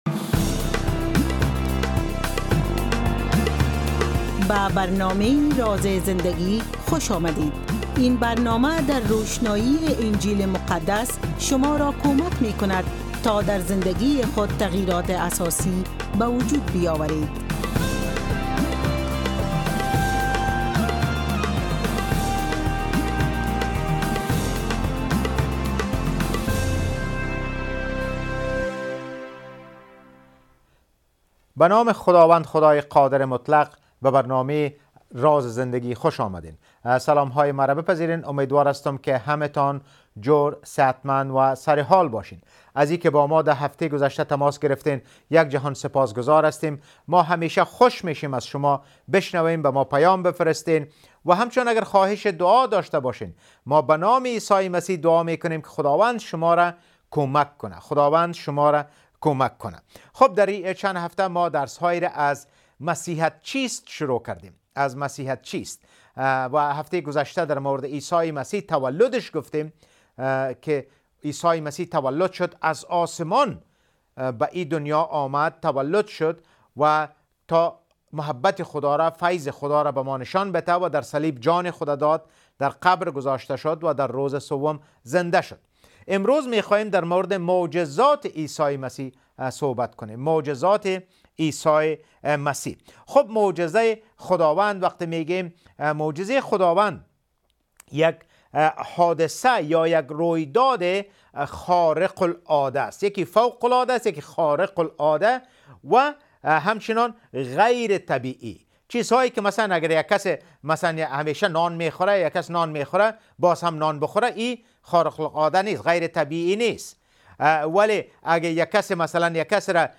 A Teaching About Prayer